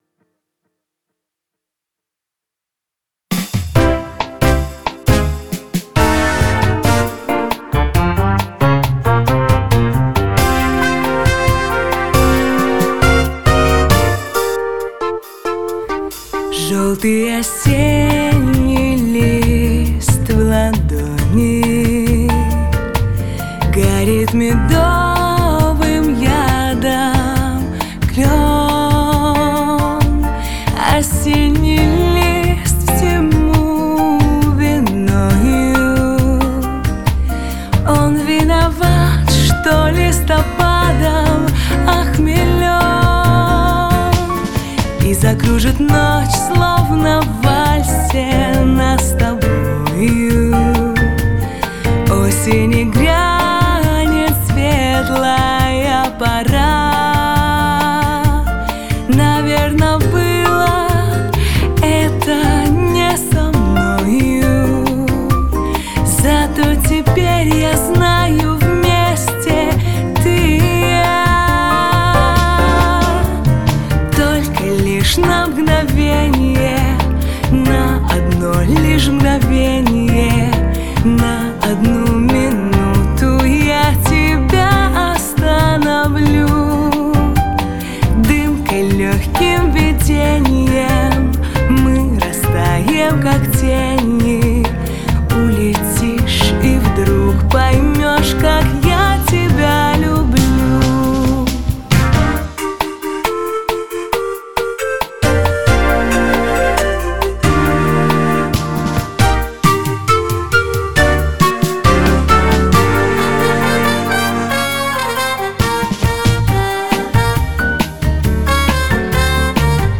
Bossa-nova
Например, 1-й куплет - кто-то эски слева тырит и тащит вправо.
С низом - да, не так, но эт правится.